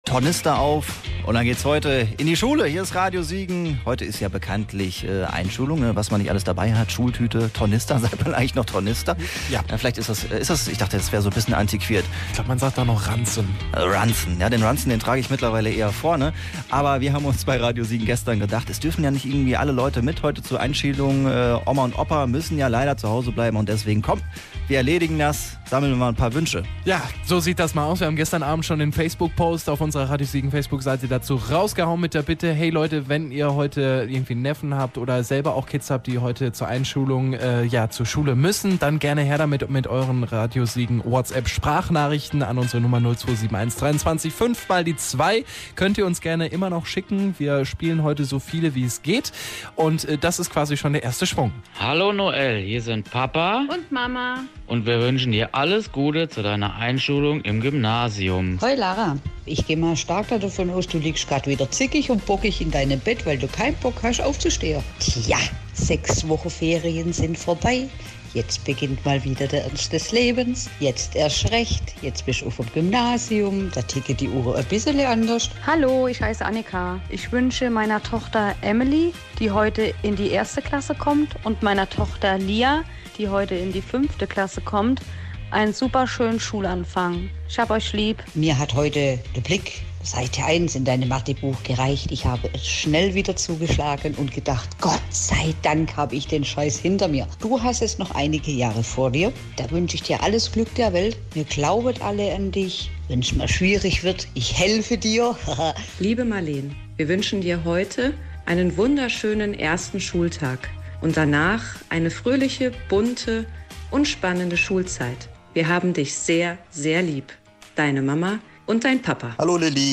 Da ja nur die engsten Verwandten mit zur Schule kommen durften, haben wir Euch Wünsche per WhatsApp Sprachnachricht schicken lassen.